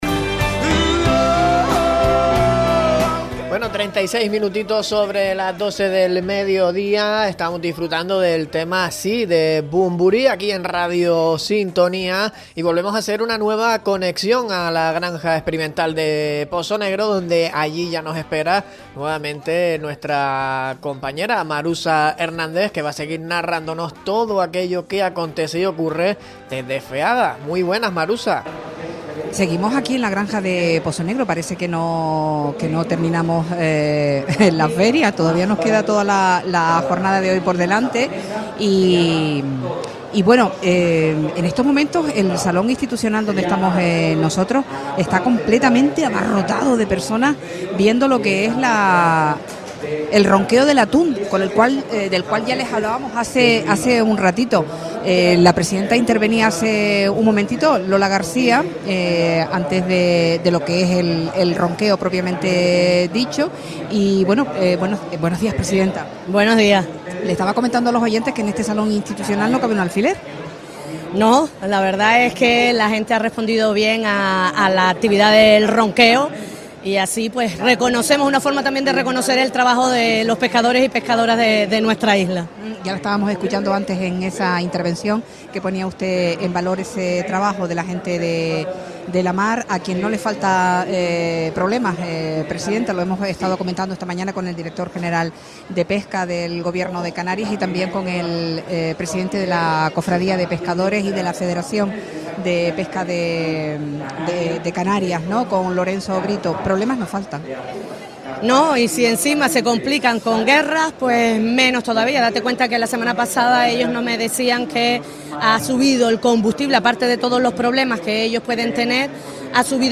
En la cuarta jornada de Feaga 2026 Lola García atiende a Radio Sintonía - Radio Sintonía
Entrevistas